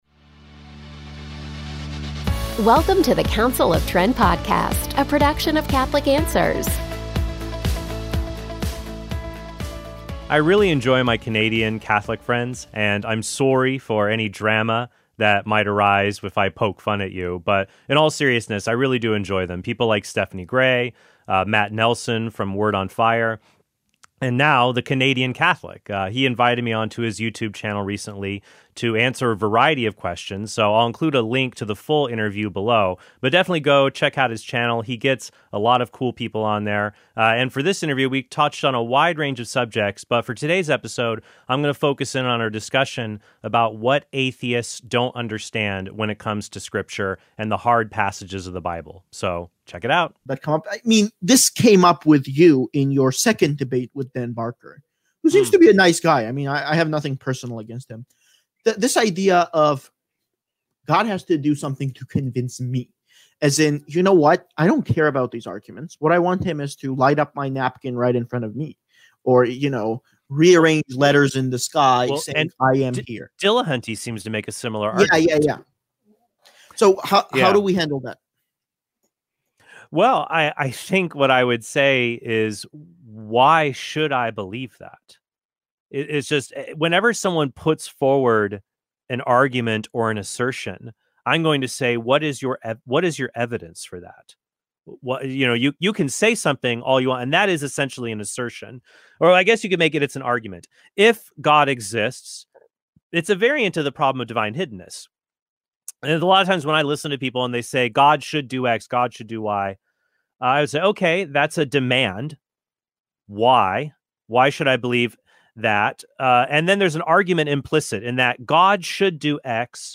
So I’ll include a link to the full interview below, but definitely go check out his channel.